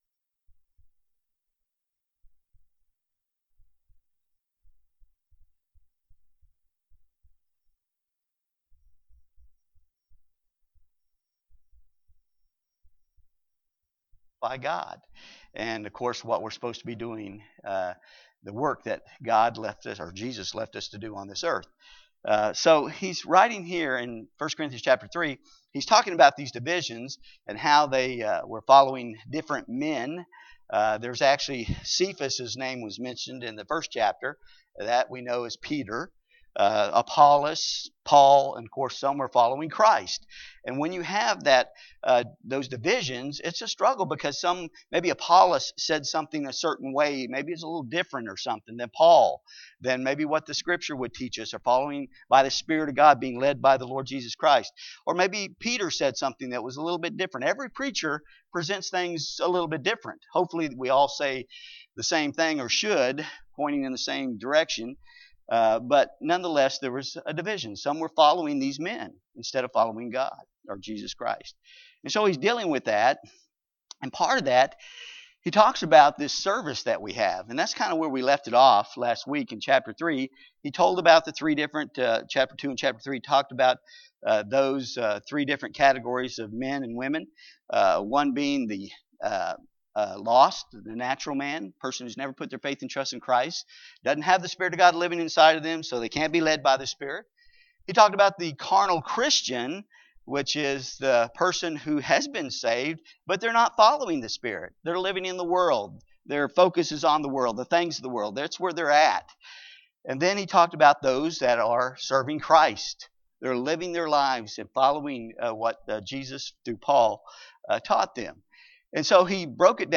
2020 A Study in the Book of 1st Corinthians Passage: 1st Corinthians 3:1-15 Service Type: Sunday School